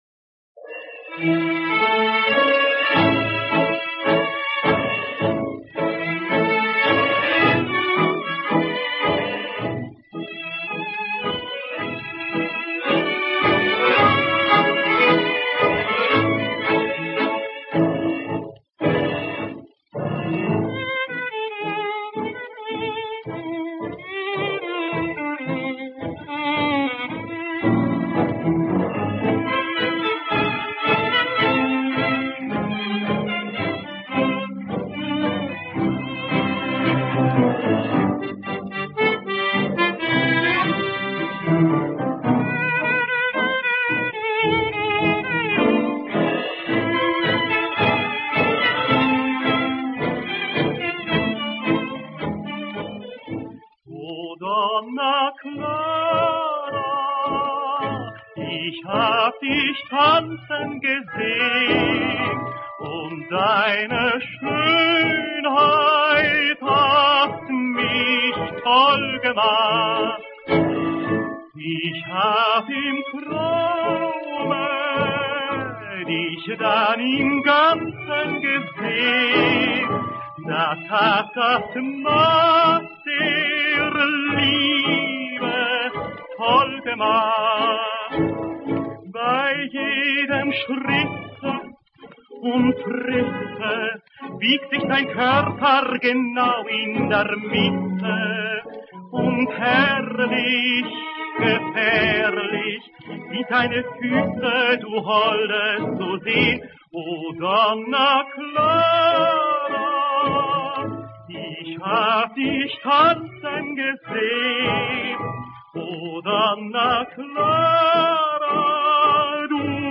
знаменитое танго конца 20-х и начала 30-х годов.